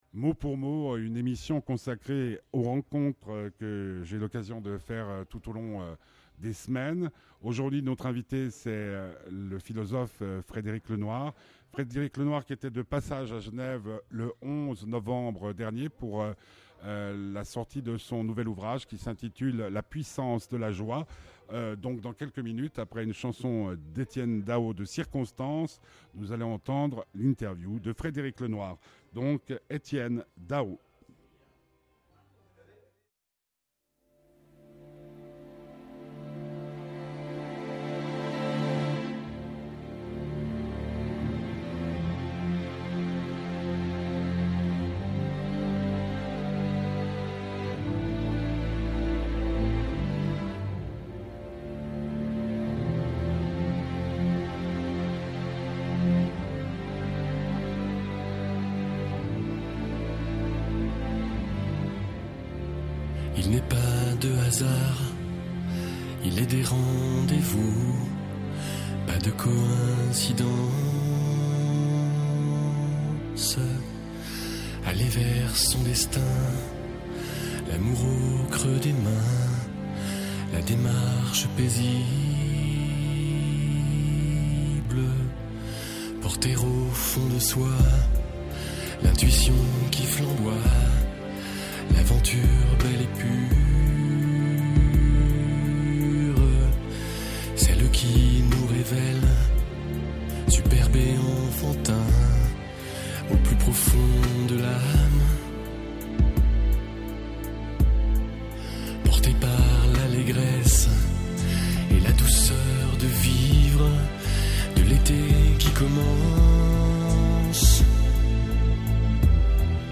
Interview de Frédéric Lenoir
le 11 novembre 2015 Lieu: cafétériat RTS - Genève Dernier ouvrage